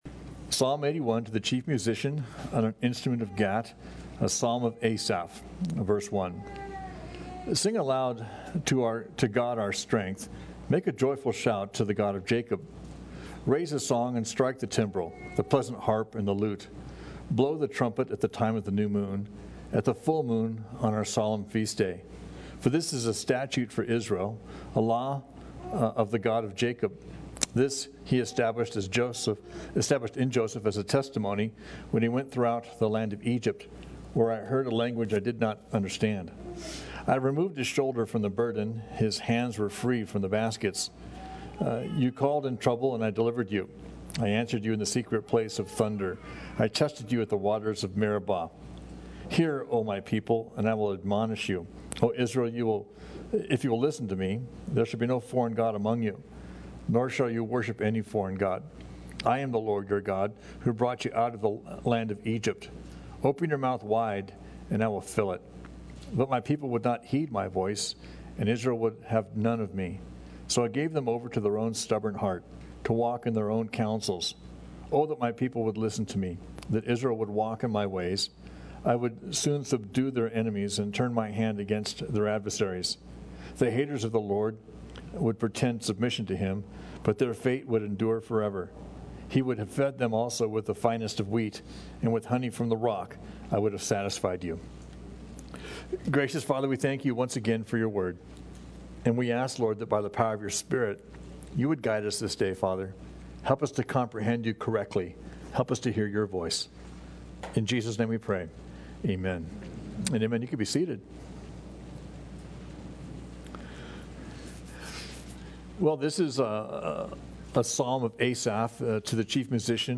teaching through the Bible on Sunday mornings and Wednesday nights